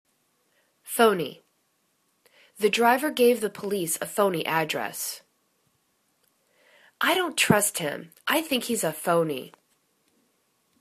pho.ny     /'fo:ni/    adj